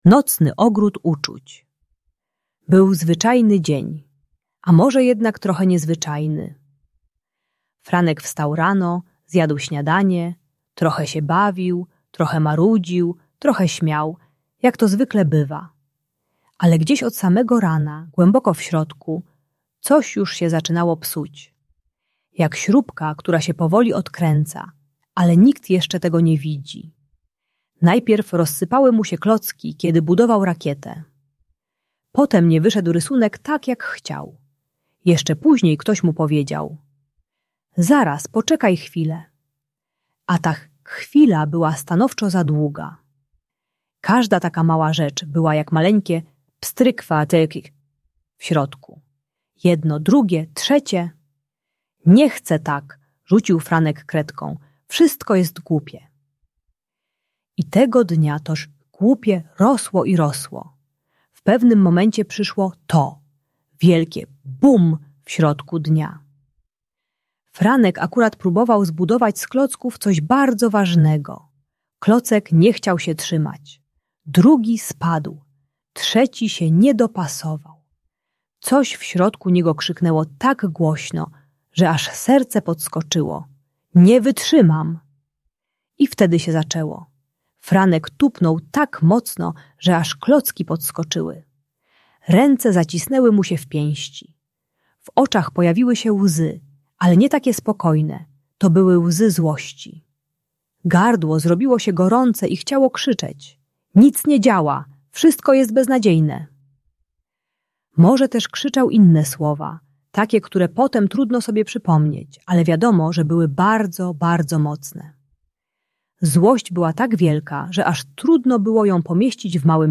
Nocny Ogród Uczuć - Bunt i wybuchy złości | Audiobajka